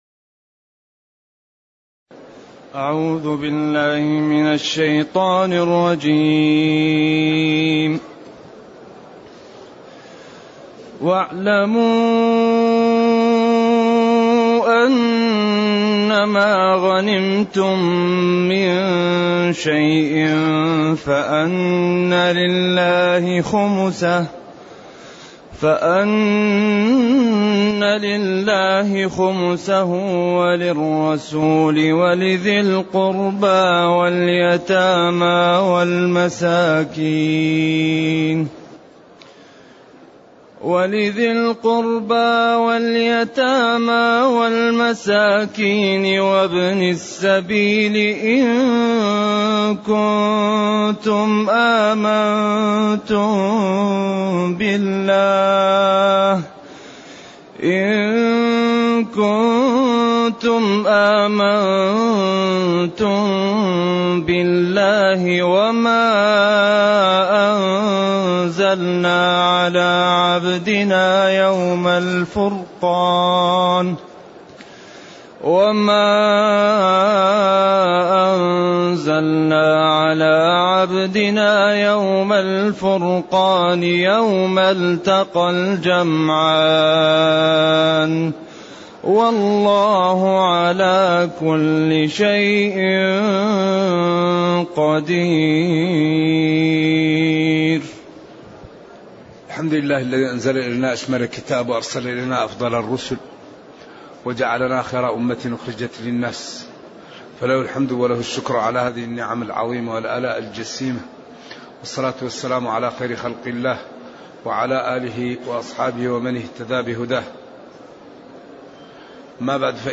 التصنيف: التفسير